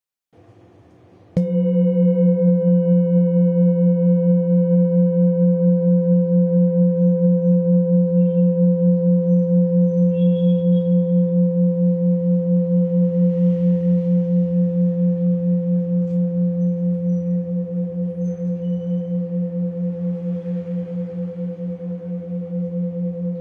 Old Hand Beaten Bronze Kopre Singing Bowl with Antique, Select Accessories
Material Bronze